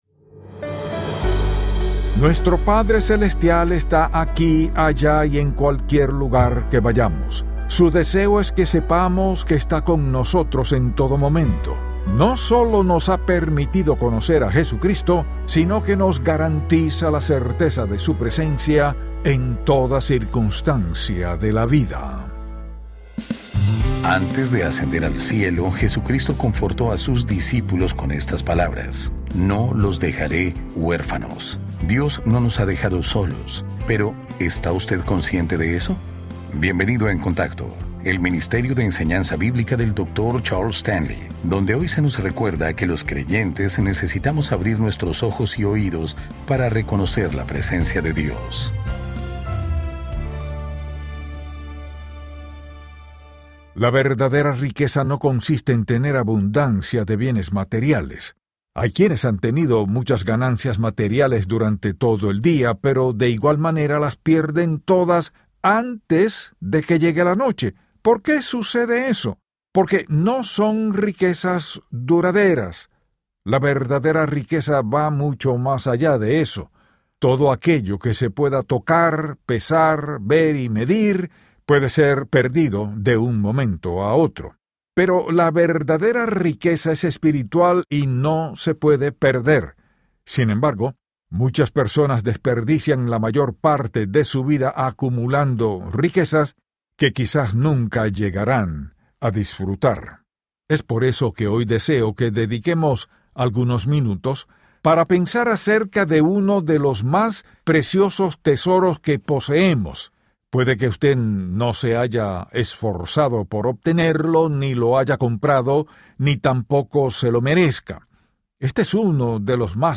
programa diario de radio In Touch Ministries.